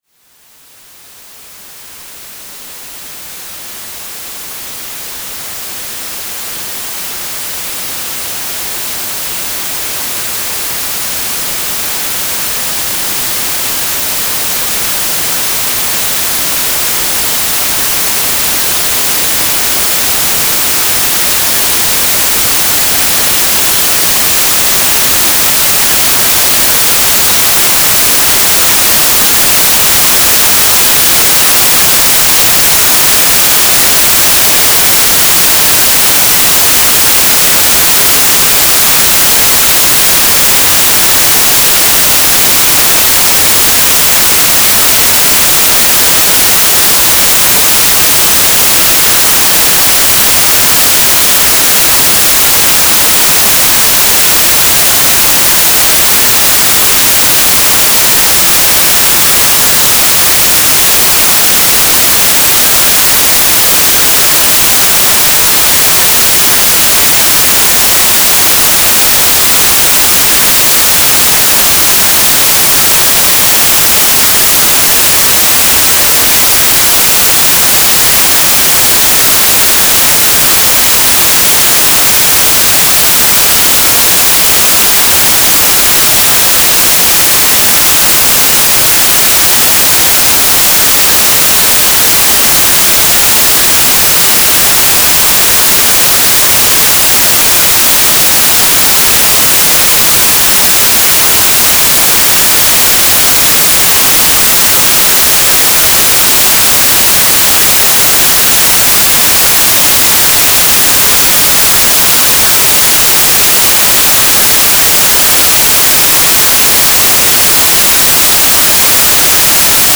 Белый шум в подарок
Запись оптимизирована под плеер, звук медленно нарастает и через 9 минут уходит в тишину, чтобы при проигрывании по кругу смягчить эффкт паузы, имеющийся во многих плеерах.
just_white_noise_1.mp3